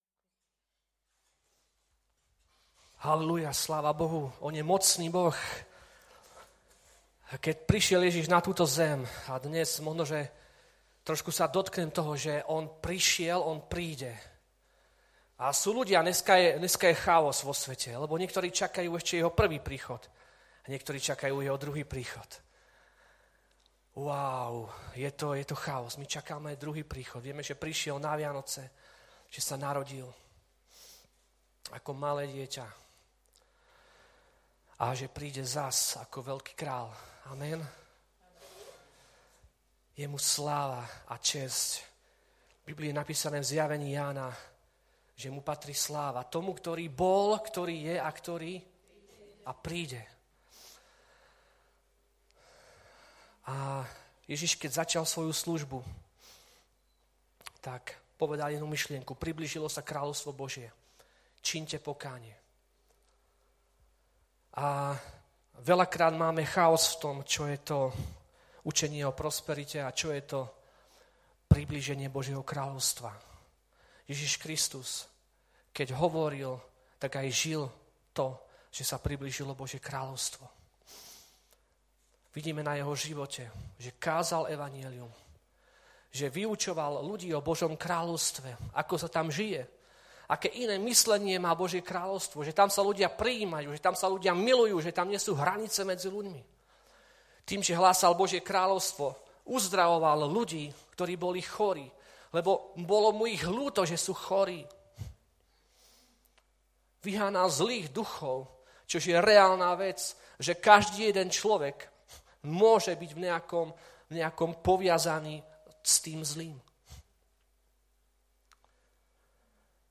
Vydané: 2018 Žáner: kázeň